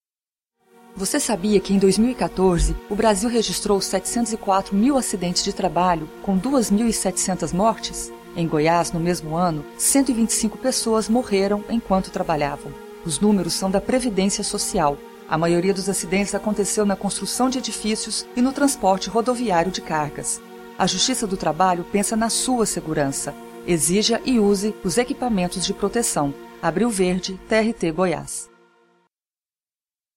Ouça abaixo os dois spots produzidos pela Rádio Web TRT Goiás para a divulgação da campanha Abril Verde:
abril-verde-spot-2.mp3